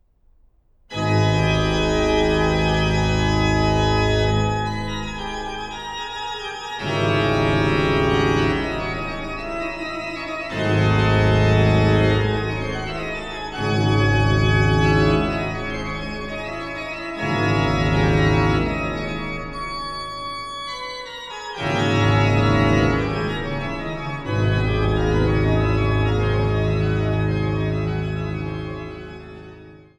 an der Großen Silbermann-Orgel im Freiberger Dom
Orgel